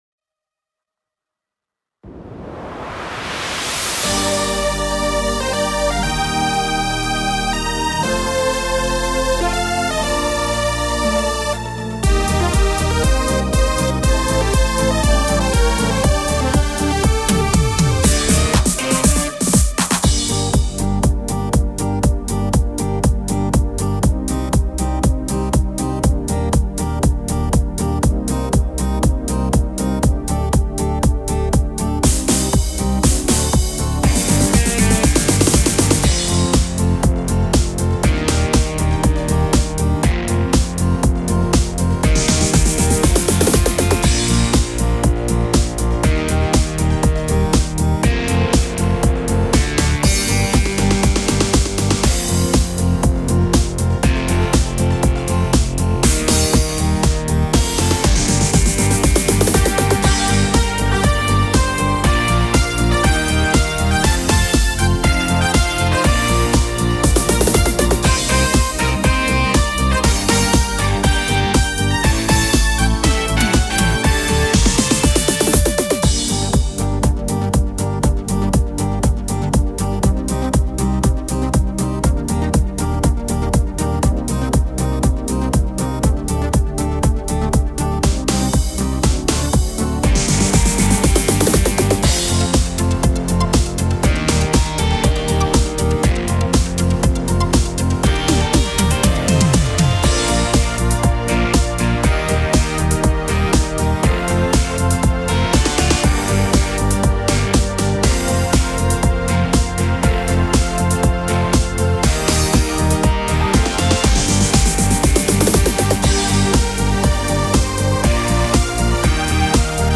Tone Tốp (Gm)
•   Beat  01.